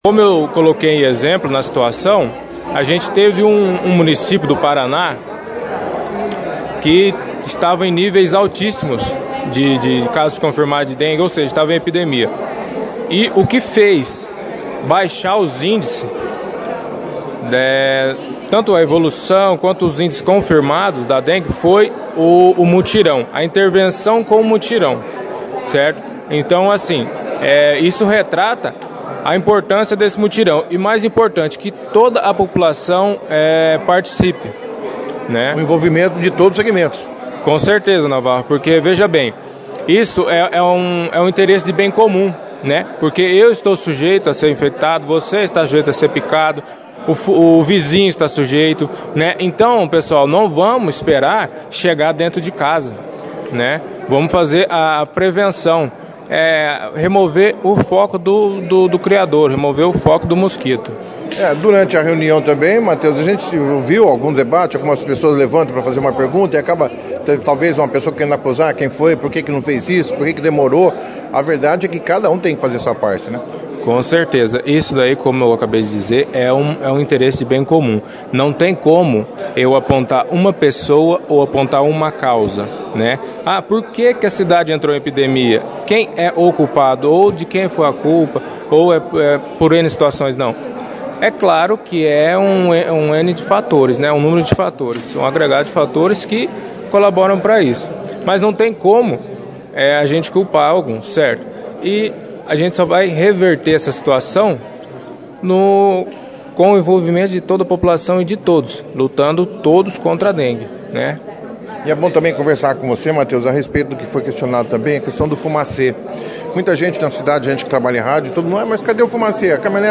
participaram da 2ª edição do jornal Operação Cidade desta quinta-feira, 20/02, falando como se dará este mutirão que tem por objetivo identificar e eliminar potenciais criadouros do mosquito Aedes aegypti, transmissor da dengue.